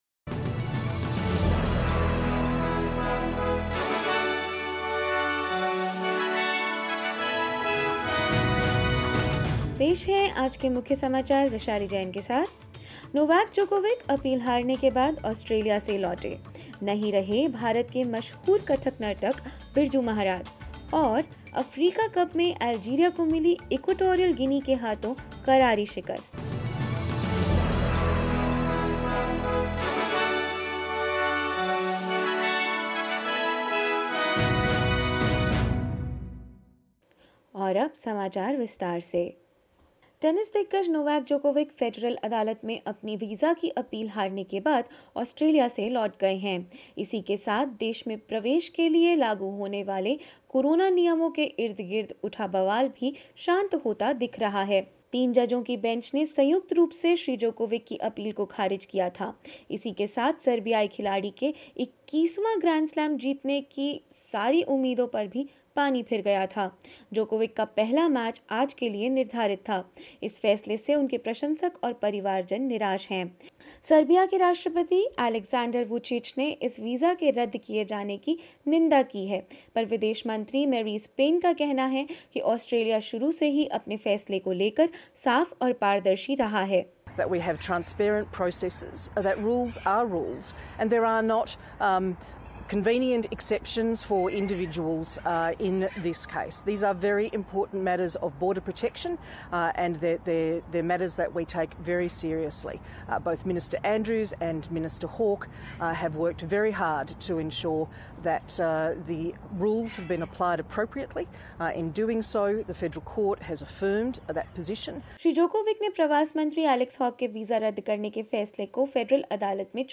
In this latest SBS Hindi bulletin: Novak Djokovic has boarded a plane out of Australia after losing an appeal against his visa cancellation; Indian Kathak legend Birju Maharaj passes away at the age of 83 and more news.